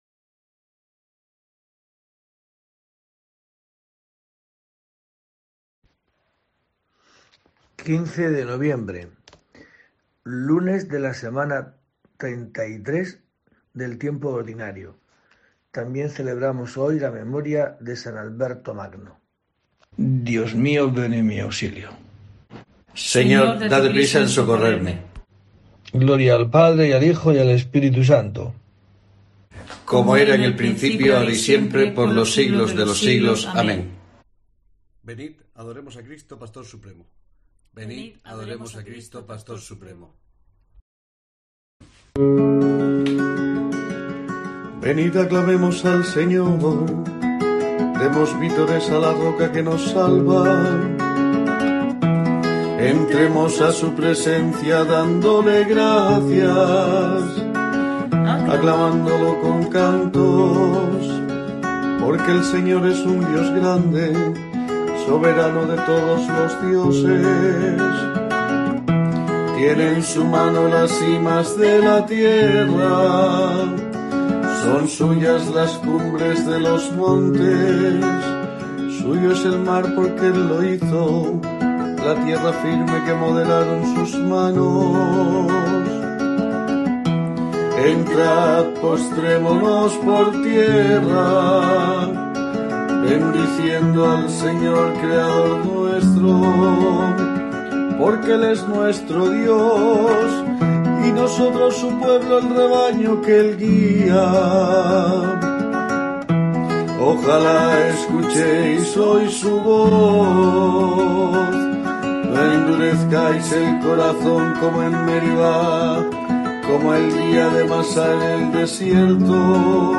15 de noviembre: COPE te trae el rezo diario de los Laudes para acompañarte